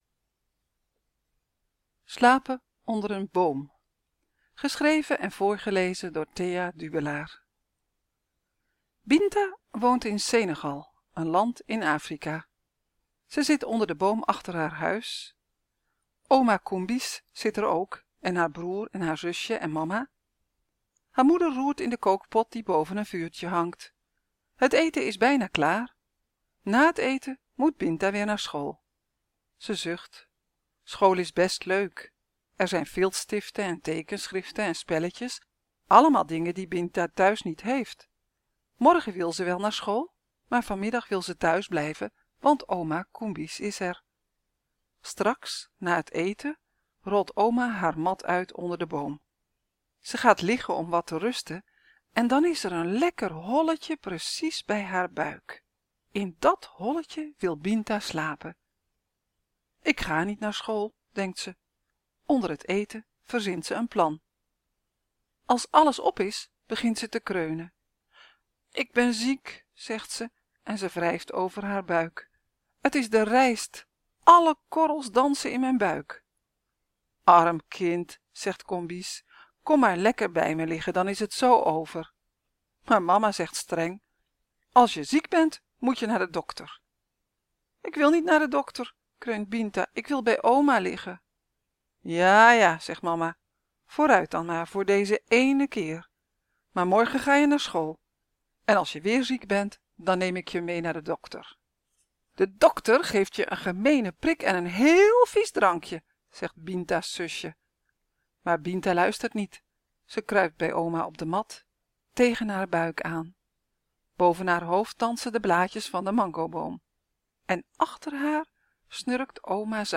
Vandaag lees ik het verhaal voor over Binta in Senegal (voor 7 jaar en ouder) Het staat in het boek ‘Vliegeren’ 50 verhalen.